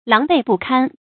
注音：ㄌㄤˊ ㄅㄟˋ ㄅㄨˋ ㄎㄢ
狼狽不堪的讀法